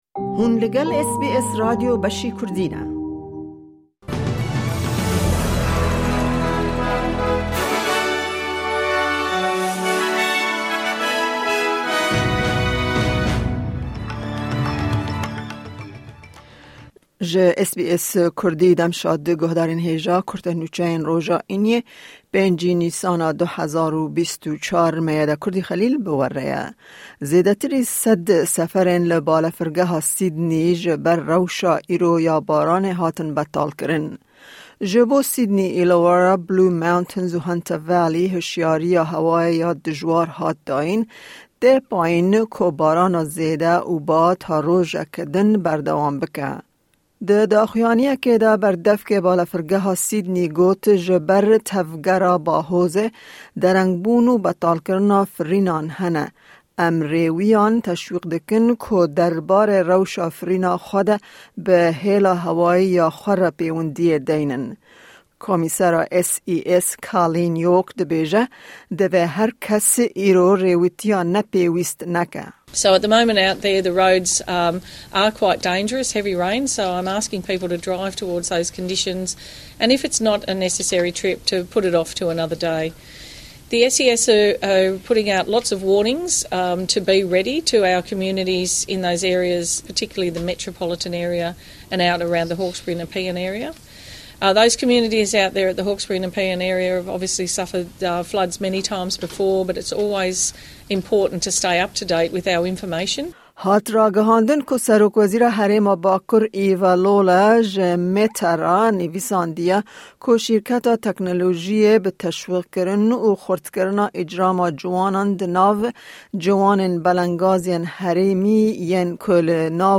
Kurte Nûçeyên roja Înî 5î Nîsana 2024